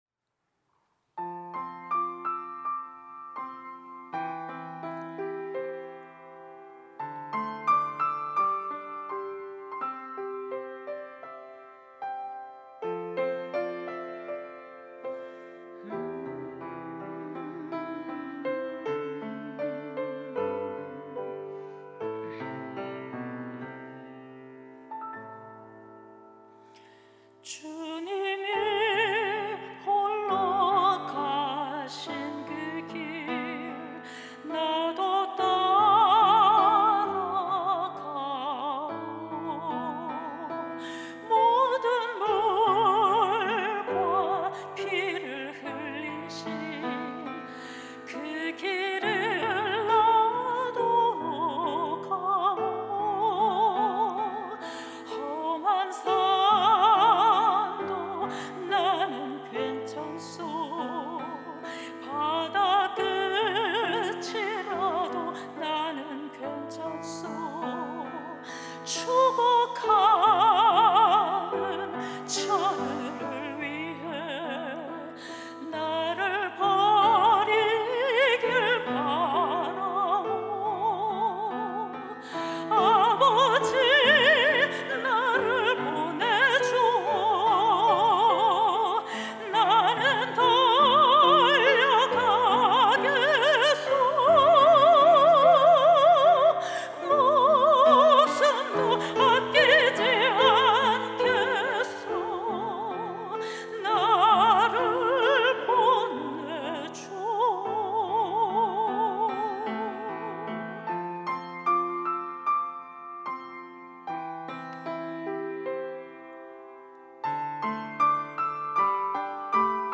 특송과 특주 - 사명